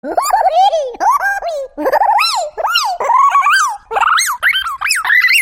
Kategorien Lustige